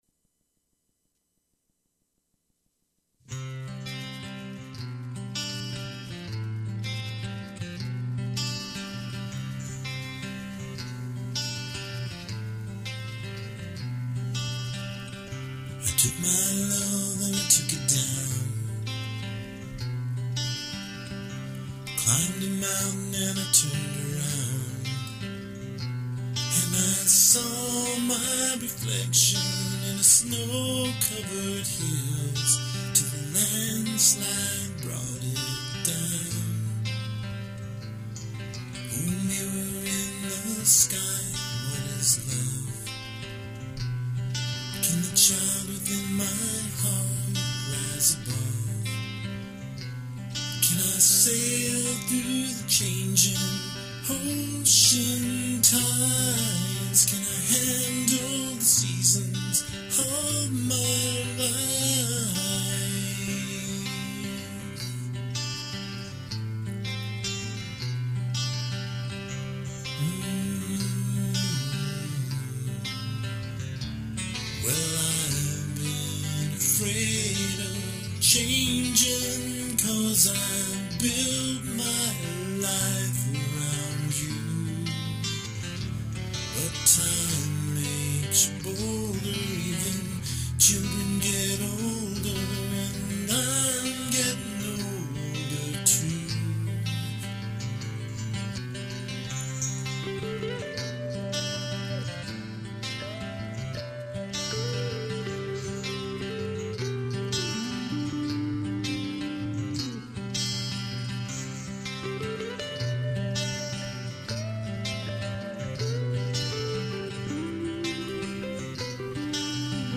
Drum machine